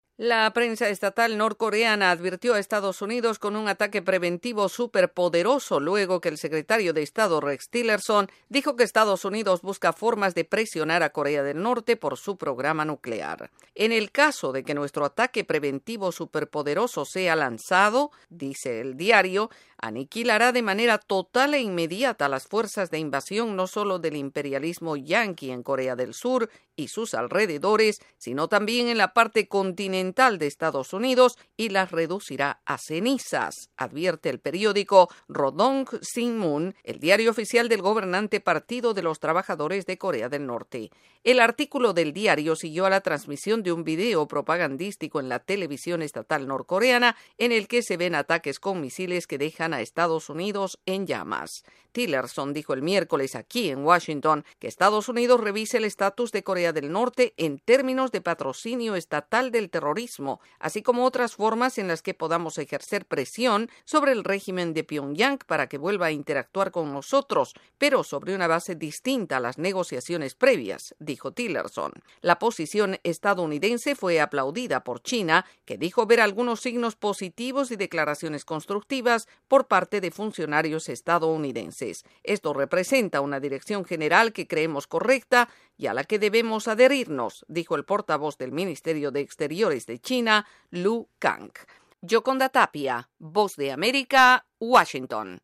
Corea del Norte emite amenazas contra Estados Unidos a través de un video y en un diario. Desde la Voz de América en Washington DC informa